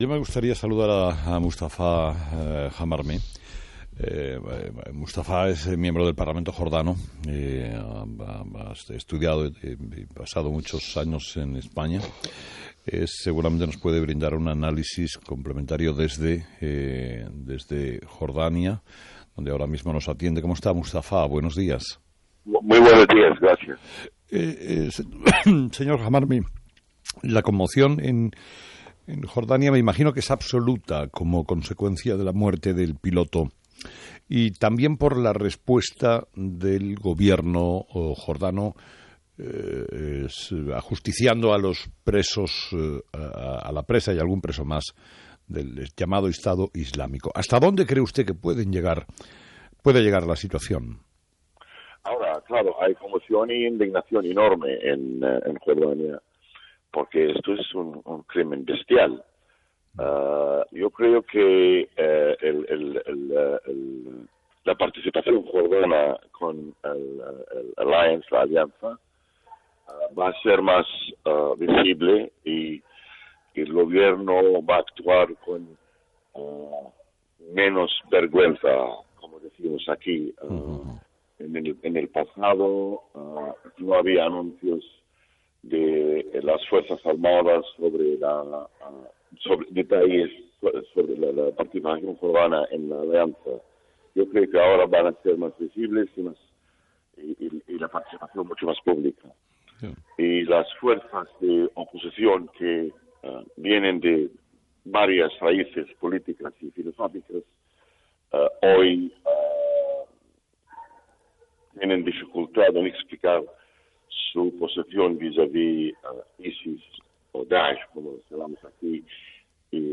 Entrevista a Mustafa Hamarneh
El miembro del Parlamento jordano, Mustafa Hamarneh, comenta en Herrera en la onda que los ciudadanos de Jordarnia se sienten "indignados", después de que el Estado Islámico haya quemado vivo al piloto Muad Kasaesbe, que tenían secuestrado .